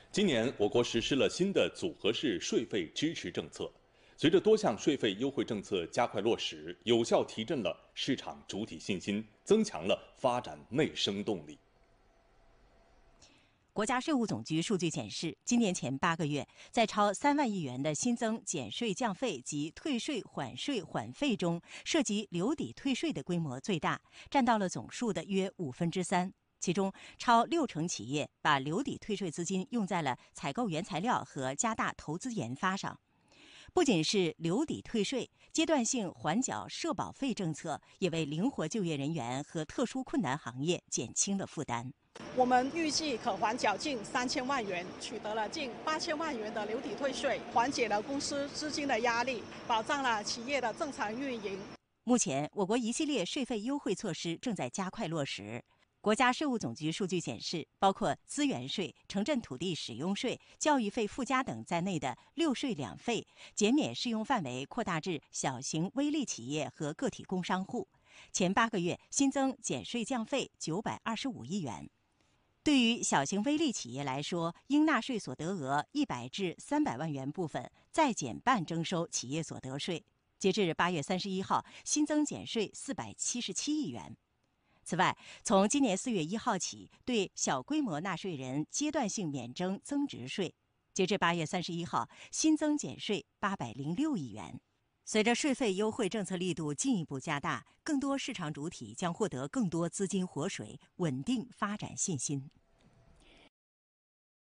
央视新闻报道 | 我国多项退税减税政策加快落实